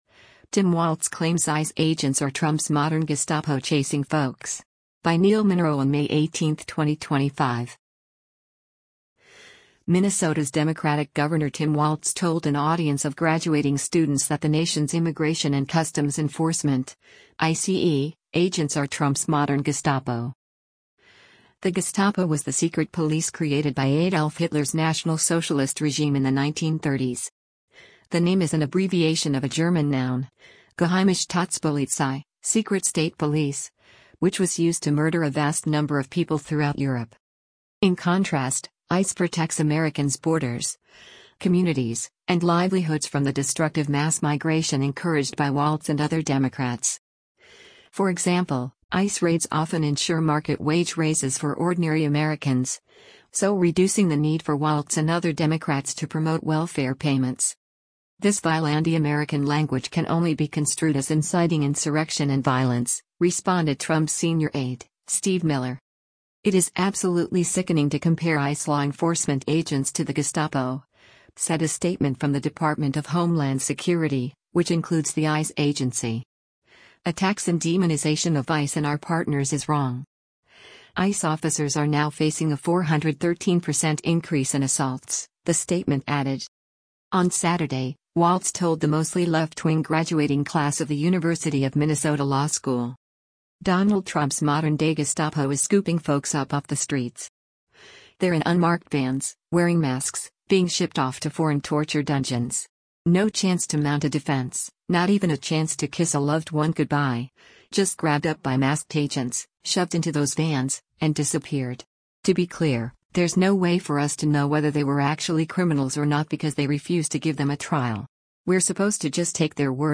Minnesota’s Democratic Gov. Tim Walz told an audience of graduating students that the nation’s Immigration and Customs Enforcement (ICE) agents are “Trump’s Modern Gestapo.”
On Saturday, Walz told the mostly left-wing graduating class of the University of Minnesota Law School: